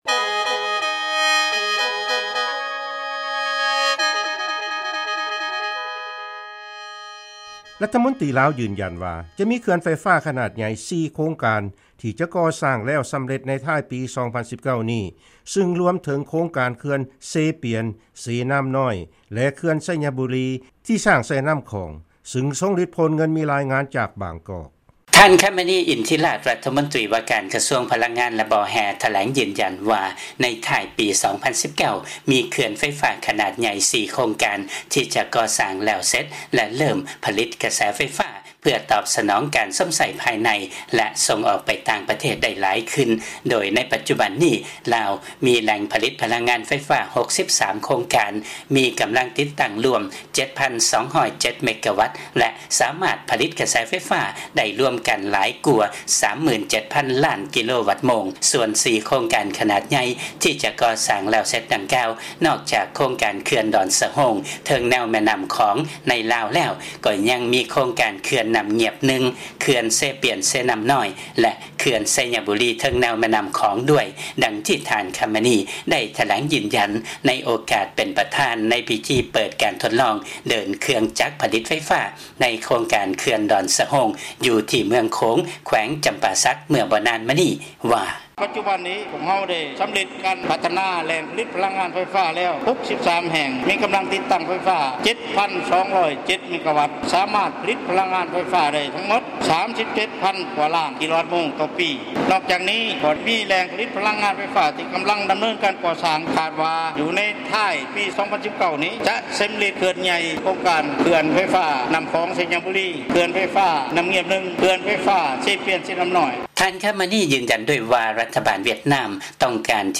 ຟັງລາຍງານ ລັດຖະມົນຕີ ລາວ ຢືນຢັນວ່າ ຈະມີເຂື່ອນໄຟຟ້າ ຂະໜາດໃຫຍ່ 4 ໂຄງການທີ່ຈະກໍ່ສ້າງແລ້ວສຳເລັດ ໃນທ້າຍປີ 2019 ນີ້